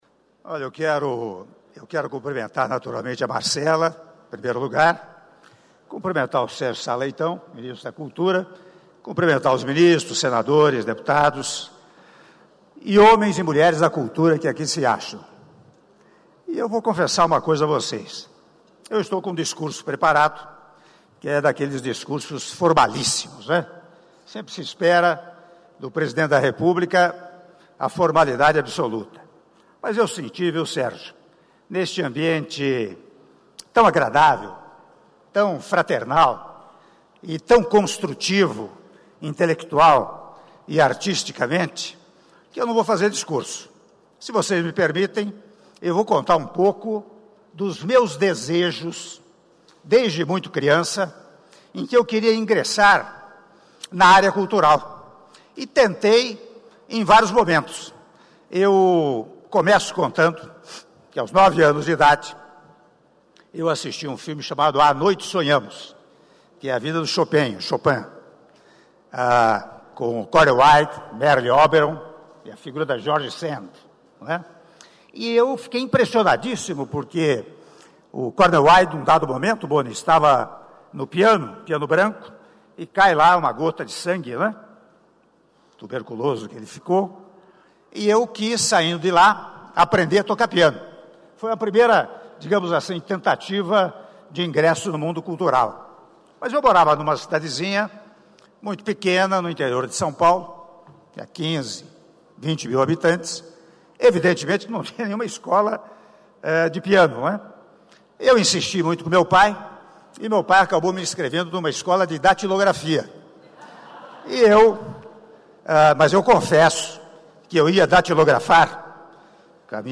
Áudio do discurso do Presidente da República, Michel Temer, durante cerimônia de entrega da Ordem do Mérito Cultural – OMC 2017 - Palácio do Planalto (07min46s)